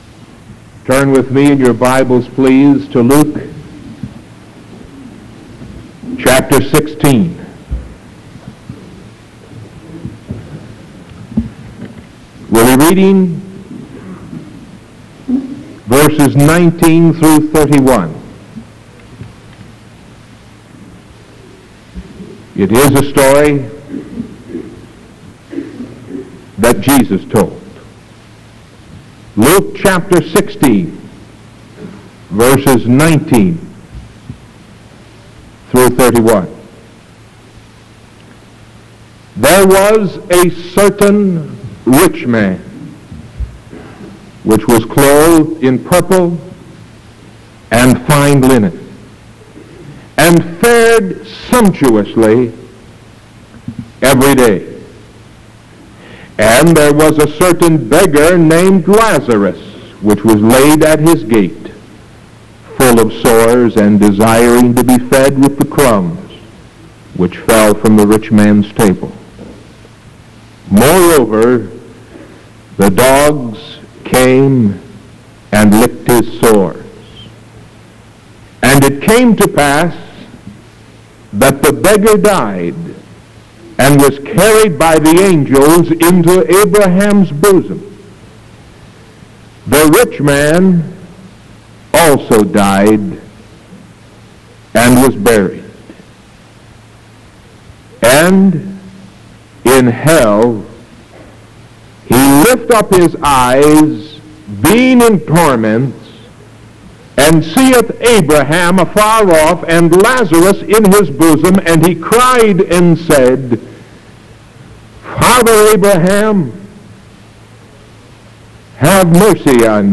Sermon September 16th 1973 PM